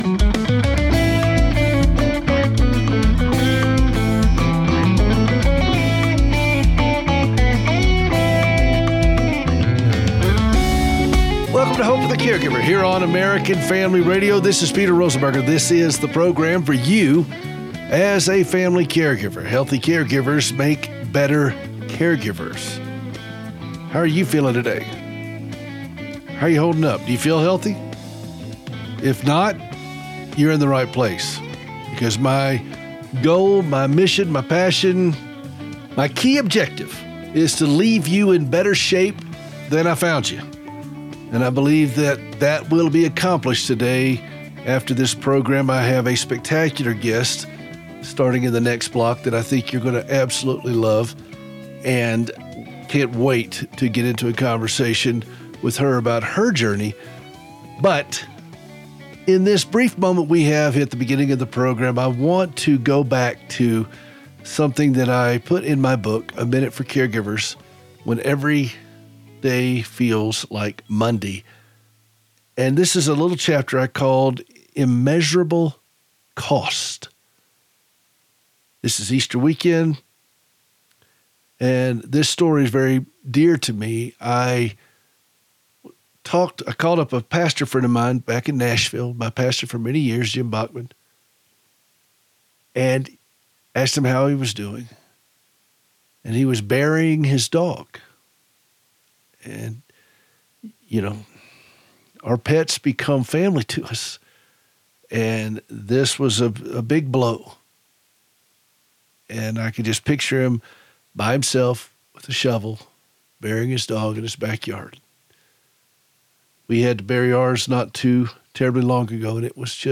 LIVE on Saturday mornings at 7:00AM.